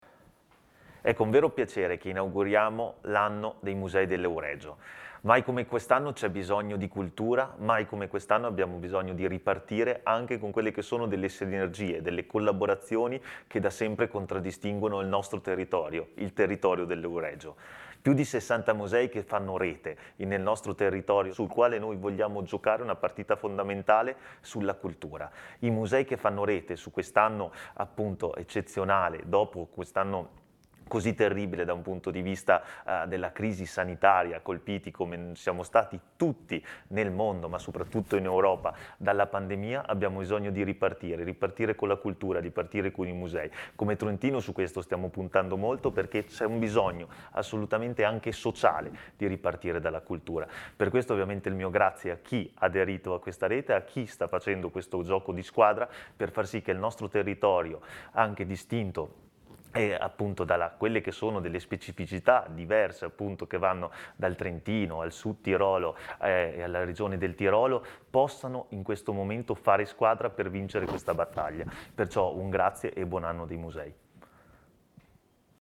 MIRKO_BISESTI_Ass._cultura_PAT.mp3